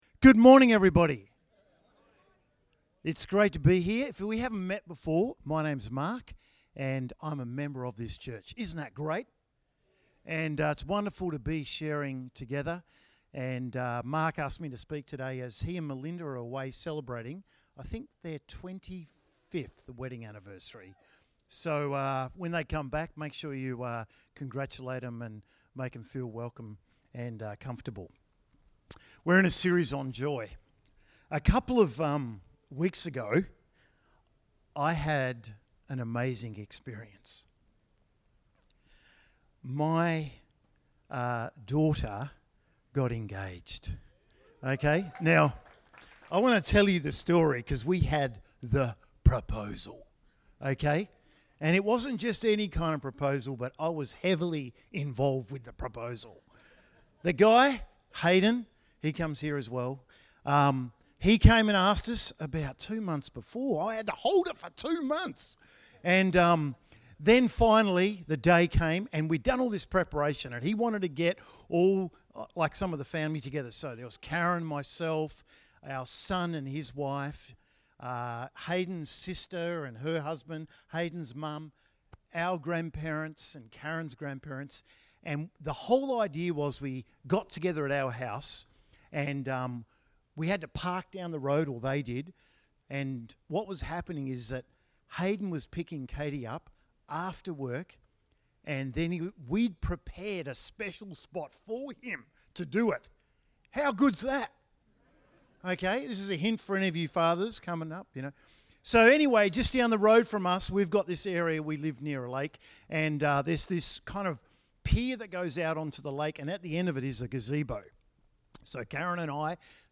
A message from the series "Wonder."